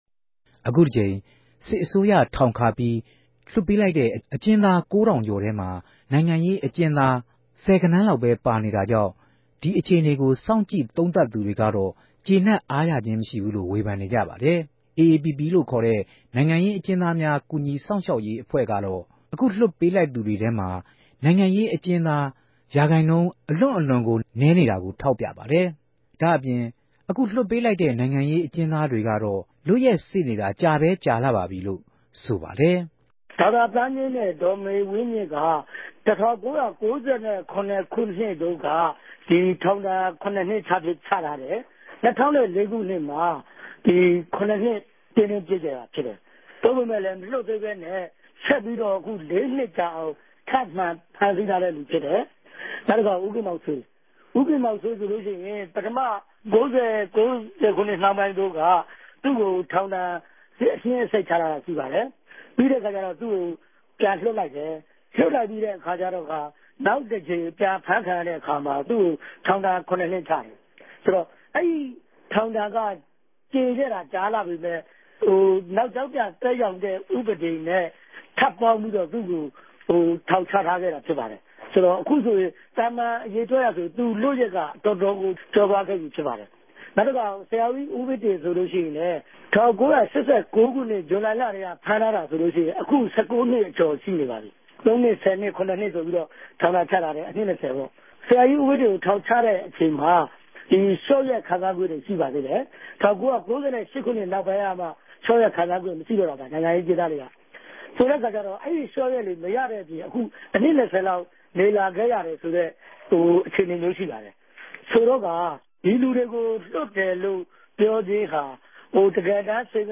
ဦးဝင်းတငိံြင့် ဆက်သြယ်မေးူမန်းခဵက်။